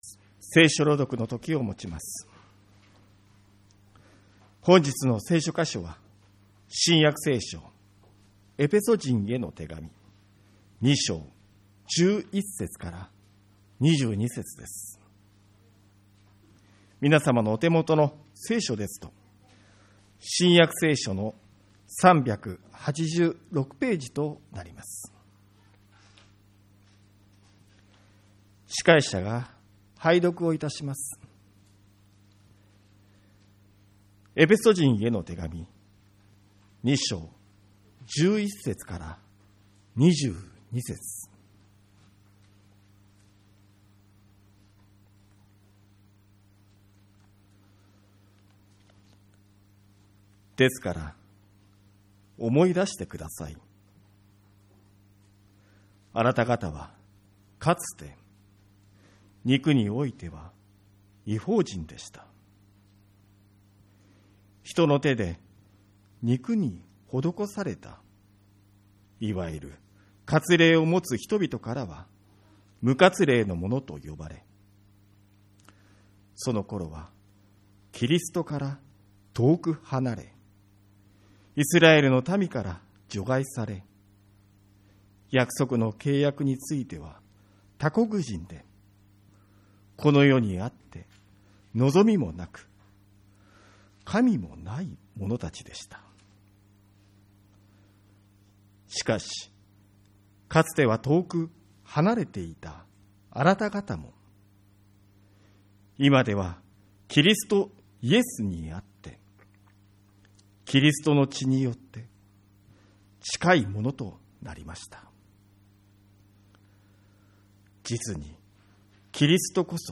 2024.1.1 元旦礼拝